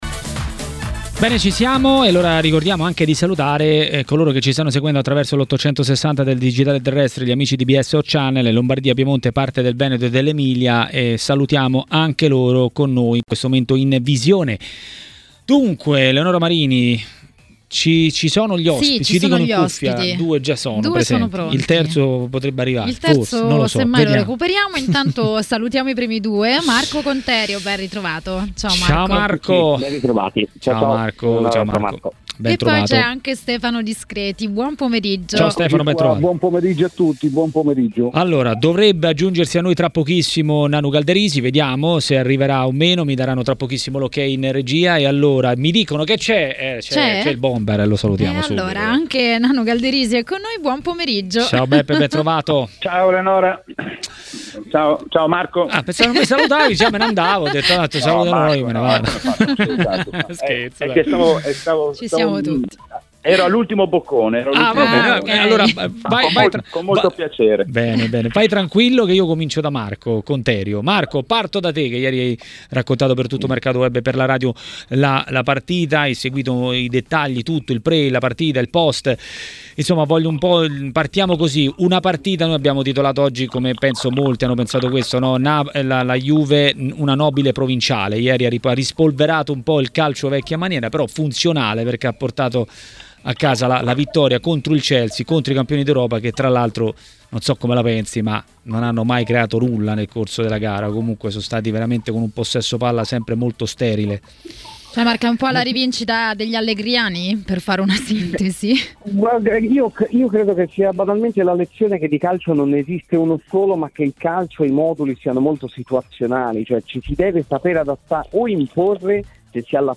A commentare le italiane in Champions a TMW Radio, durante Maracanà, è stato il bomber Giuseppe Galderisi.